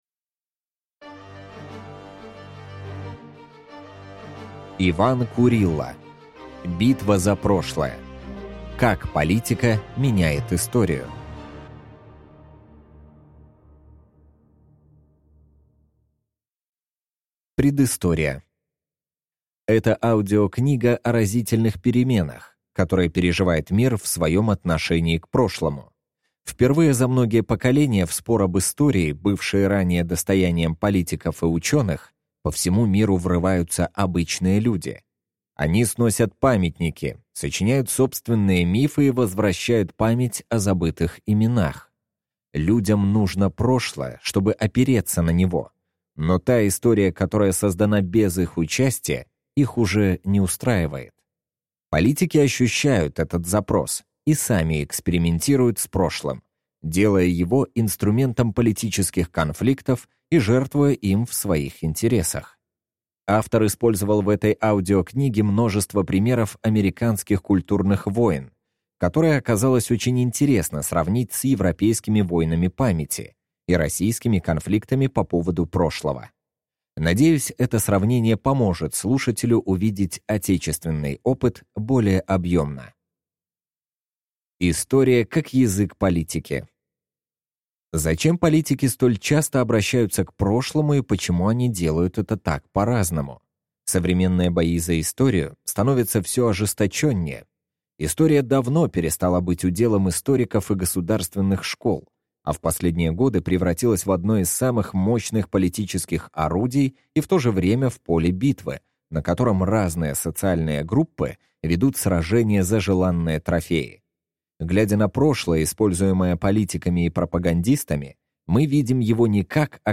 Аудиокнига Битва за прошлое. Как политика меняет историю | Библиотека аудиокниг